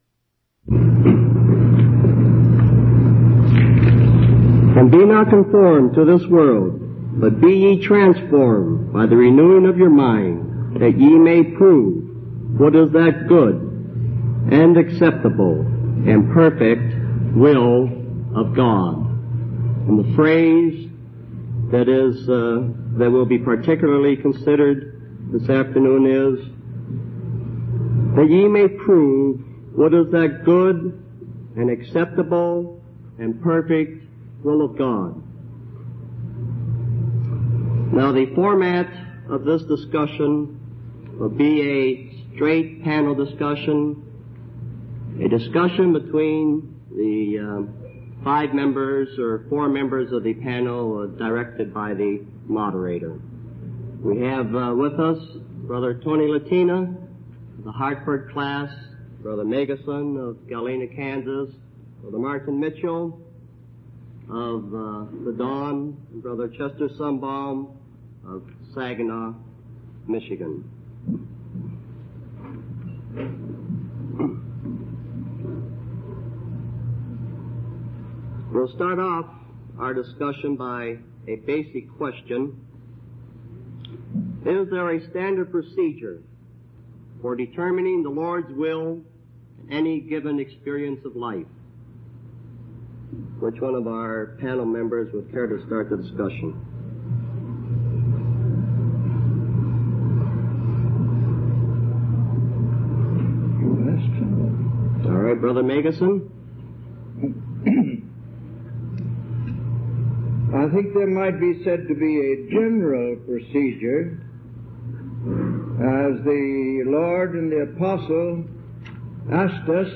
From Type: "Panel"
Related Topics: Christian Walk | More Messages from Panel Discussion | Download Audio